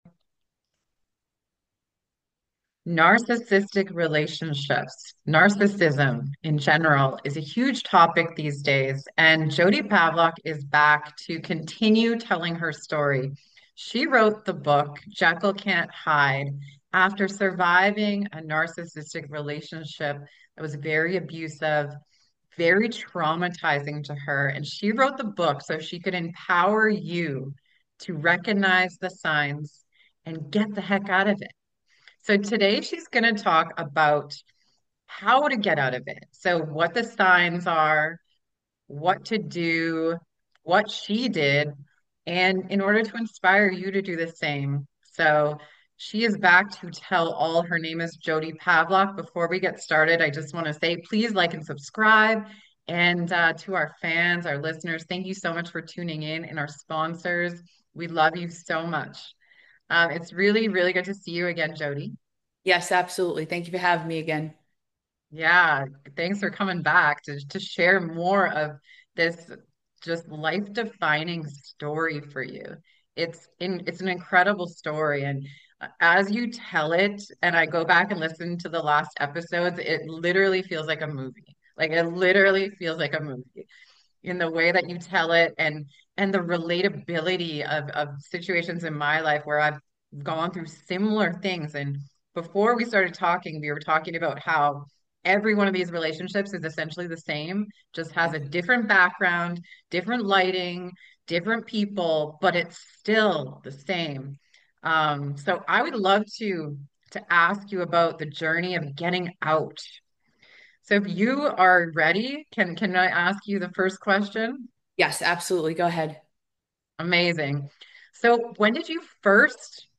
👉 Love insightful and life-changing interviews?